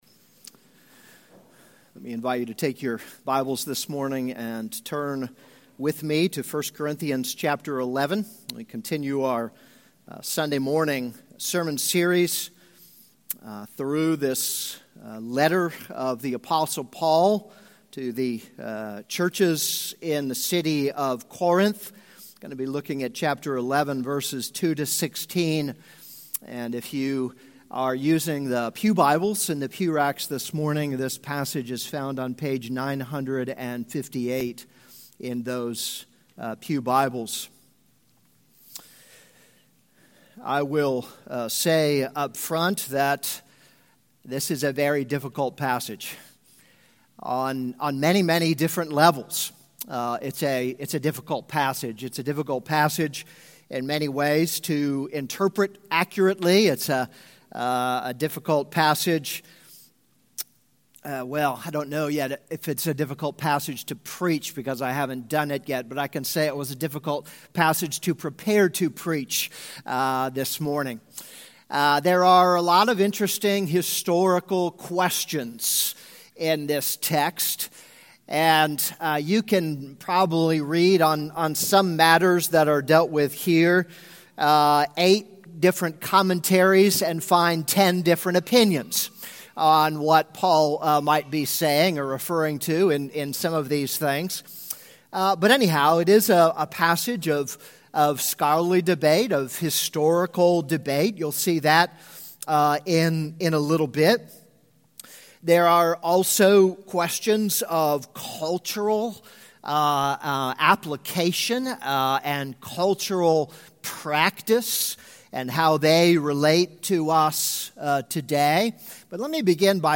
This is a sermon on 1 Corinthians 11:2-16.